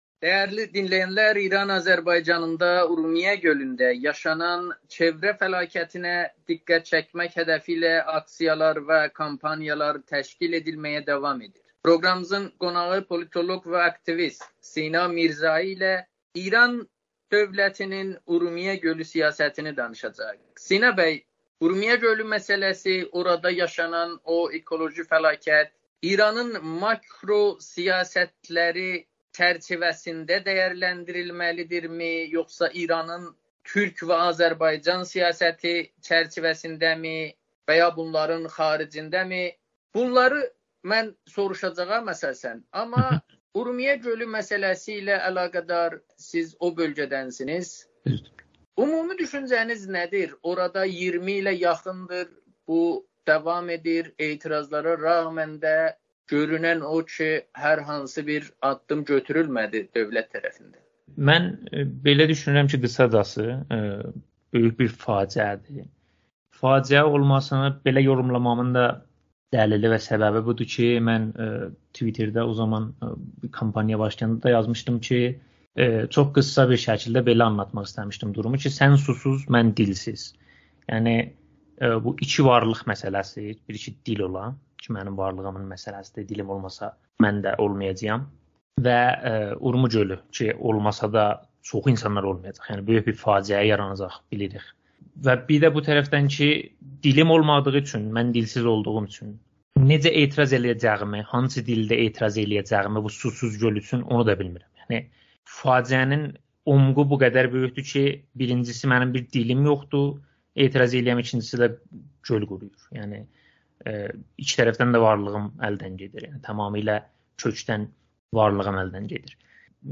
Amerikanın Səsinə müsahibəsində